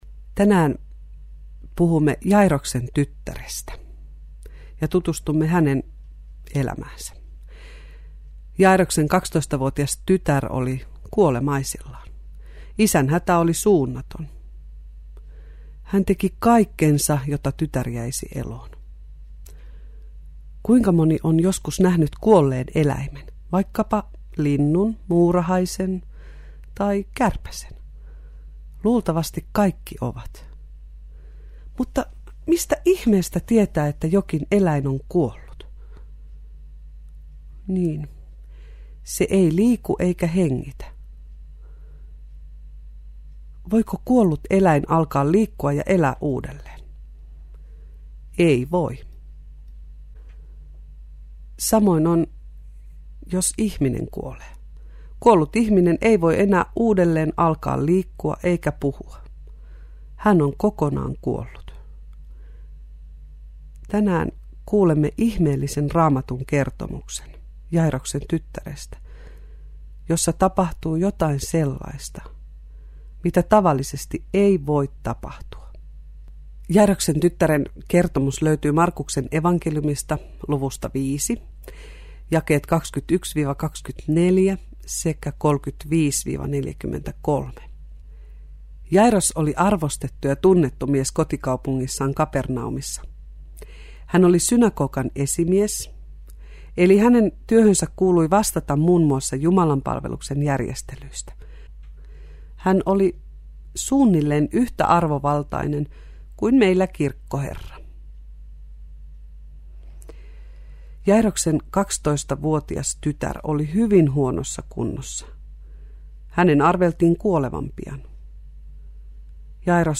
Julkaistu alun perin syksyllä 2010 Radio Deissä lasten Seikkaillen läpi Raamatun -raamattuohjelmana.